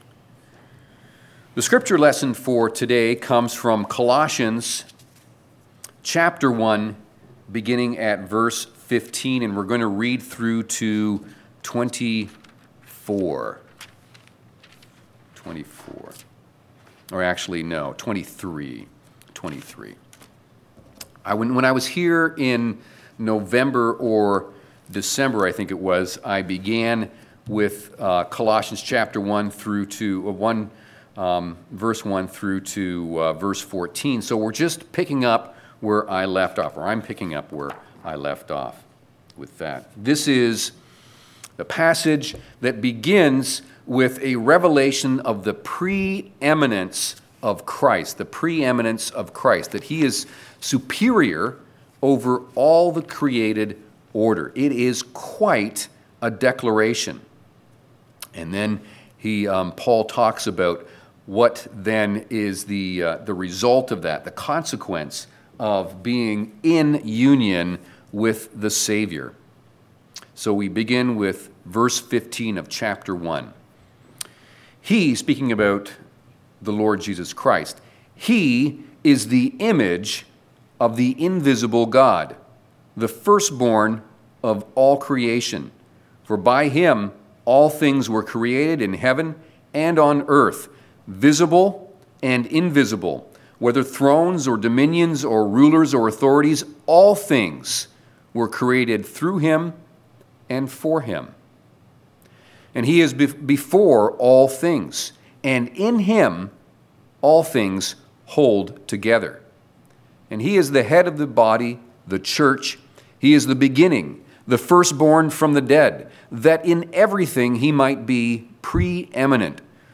Service Type: Sunday Afternoon
5.-Sermon.mp3